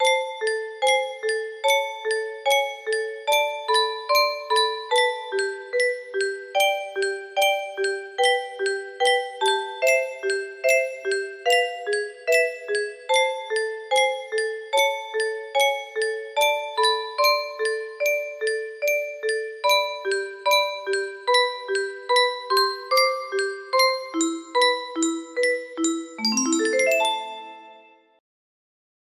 Songs Lost, Yet Not Forgotten music box melody